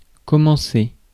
Ääntäminen
IPA: [kɔ.mɑ̃.se]